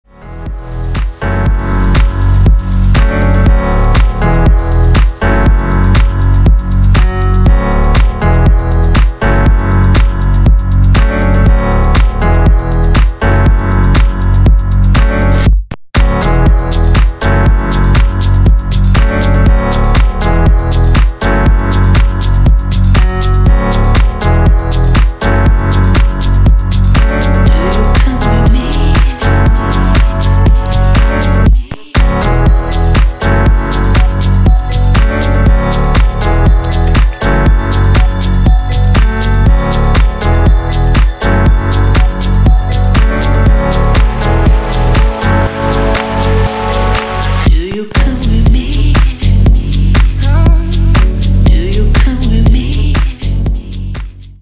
• Качество: 320, Stereo
dance
Electronic
Indie Dance
Piano
Стиль: deep house, nu disco